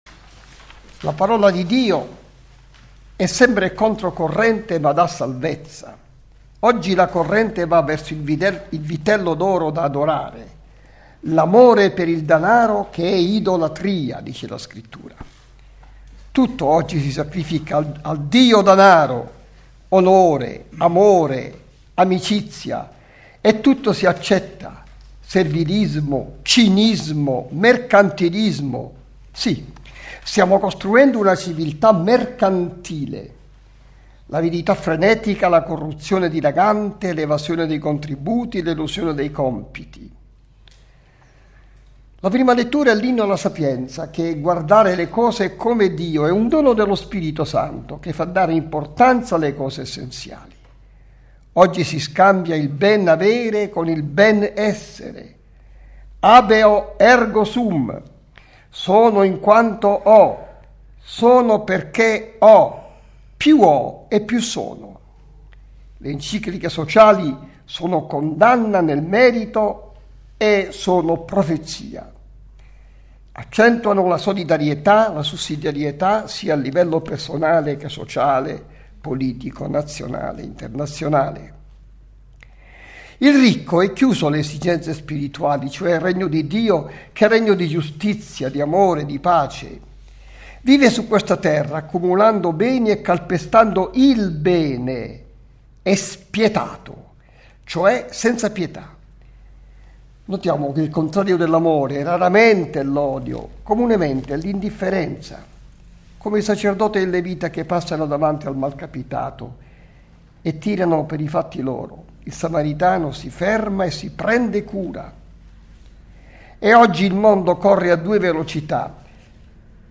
Santa Messa in collegamento RAI-Radio1
Ogni Domenica alle 9.30 dalla Cappella Leone XIII all'interno dei Giardini Vaticani, viene trasmessa la Santa Messa secondo le intenzioni del Sommo Pontefice Benedetto XVI.